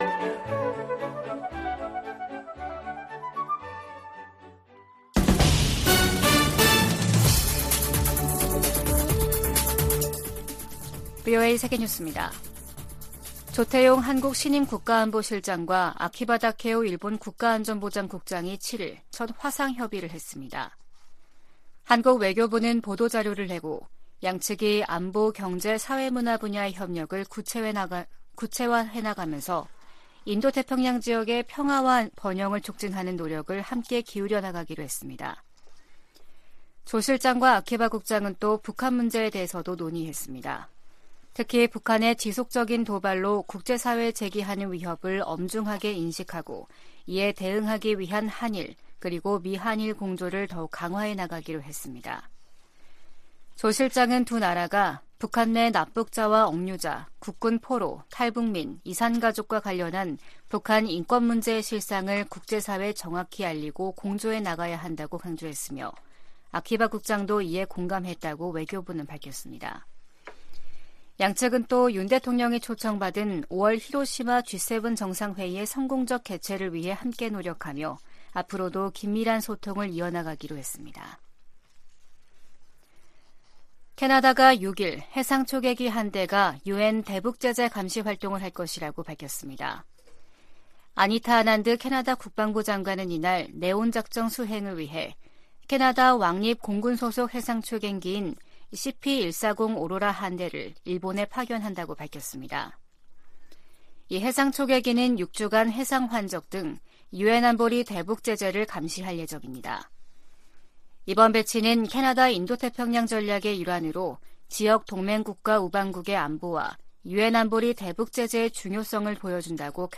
VOA 한국어 아침 뉴스 프로그램 '워싱턴 뉴스 광장', 4월 8일 방송입니다. 미한일 북핵 수석대표들은 서울에서 공동성명을 발표하고 모든 유엔 회원국들에 유엔 안보리 결의를 완전히 이행할 것을 촉구했습니다. 백악관은 북한이 대화에 복귀하도록 중국이 영향력을 발휘할 것을 촉구했습니다.